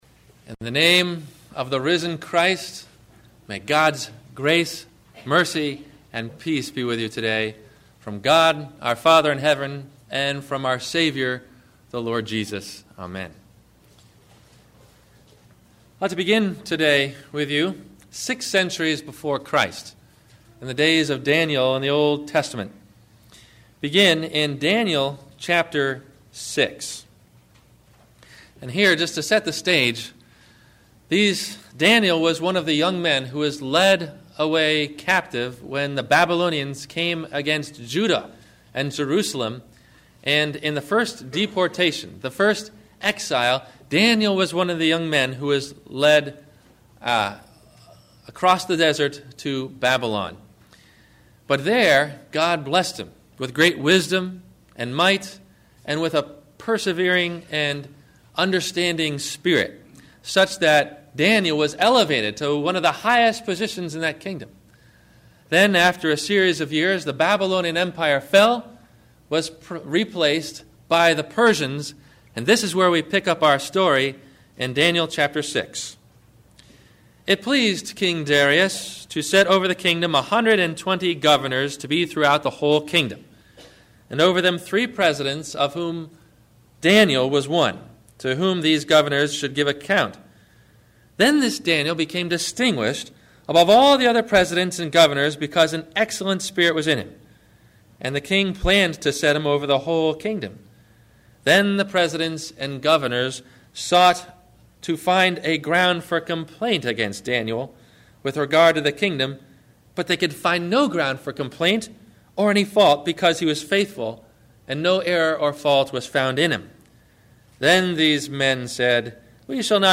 Easter – The Great Stone – Sermon – March 23 2008
Easter Sunday